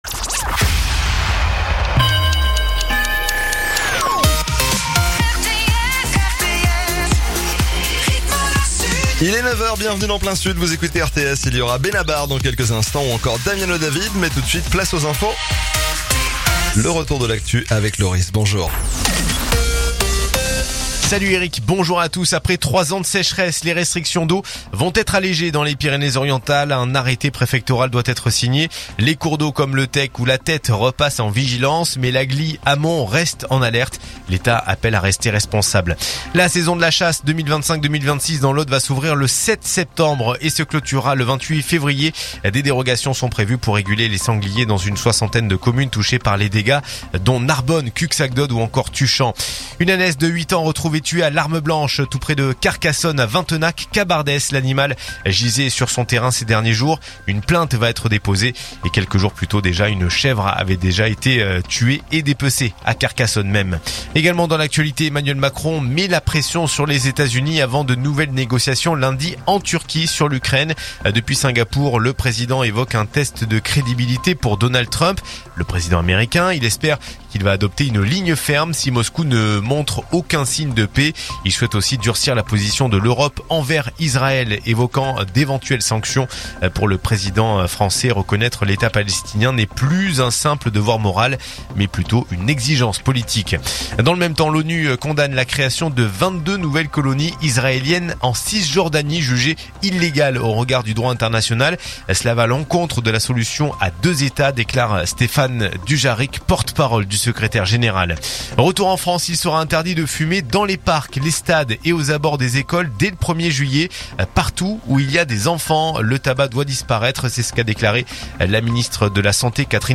info_narbonne_toulouse_399.mp3